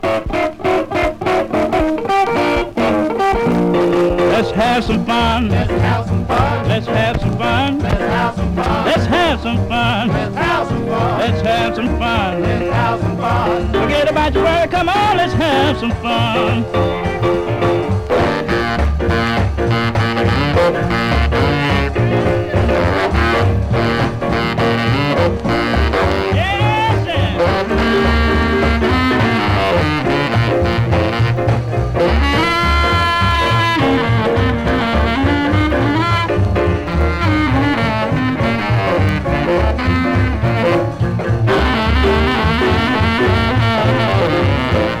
R&B, Blues, Jump, Louisiana　USA　12inchレコード　33rpm　Mono